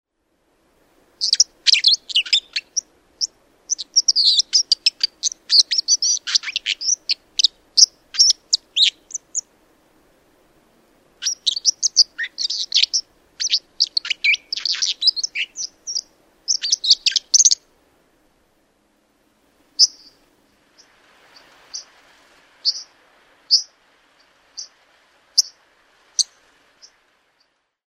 Pesä on miltei aina aivan virtaavan veden äärellä, joten koskikaran elämän näyttämön solina ja kohina on poikasille tuttua jo niiden elämän alussa.
Cinclus cinclus
Ääni ja laulu: Kirkasta ja kirskuvaa ääntelyä ja laulua, joka kantaa koskenkin kumussa.